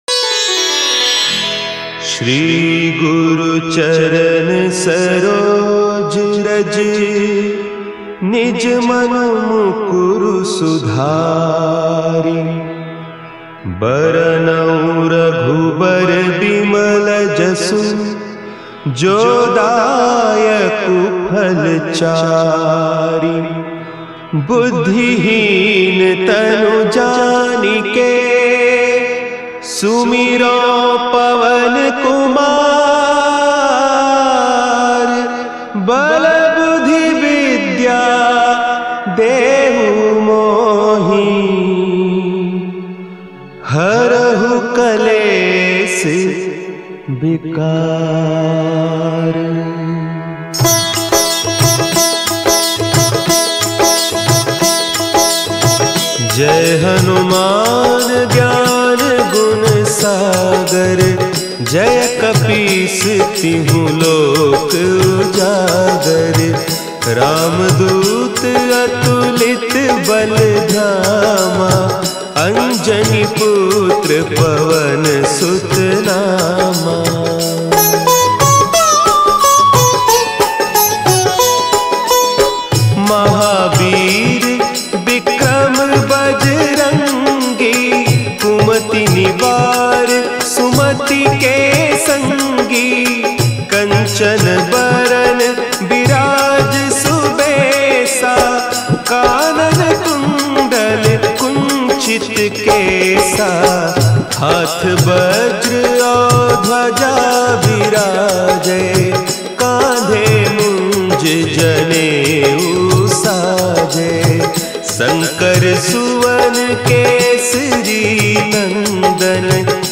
भक्ति गीत
संगीतमय पाठ